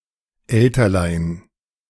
Elterlein (German pronunciation: [ˈɛltɐlaɪ̯n]
De-Elterlein.ogg.mp3